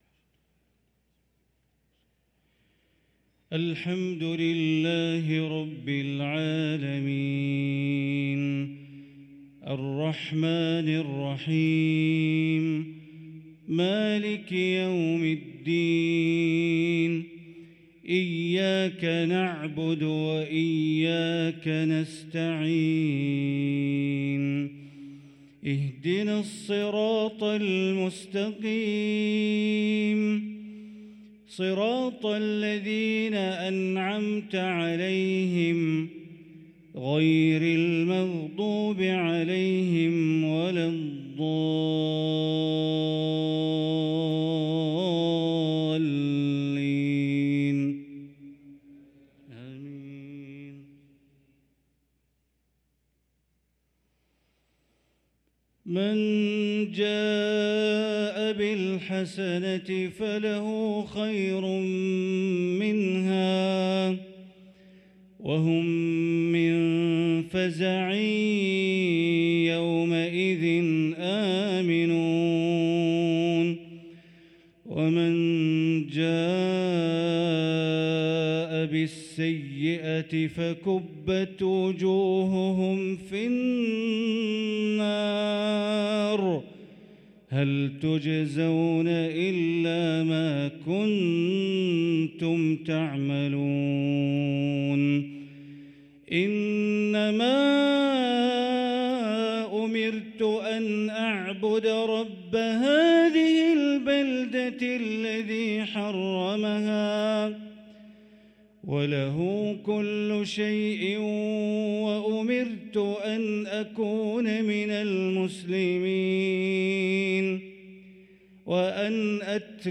صلاة العشاء للقارئ بندر بليلة 27 صفر 1445 هـ